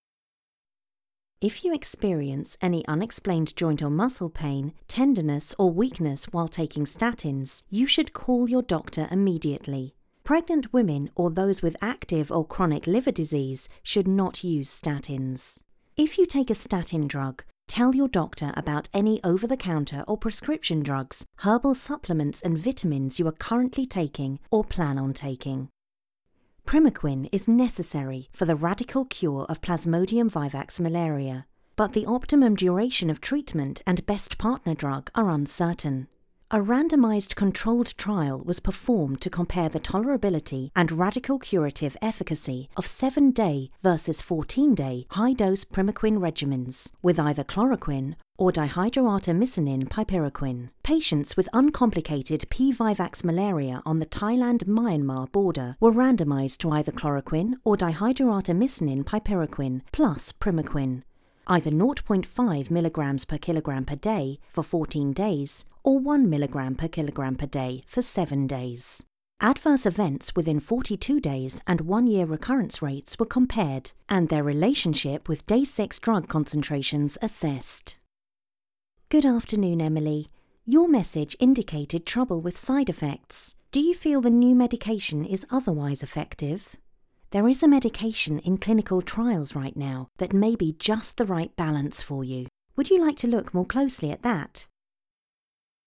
Voice Reels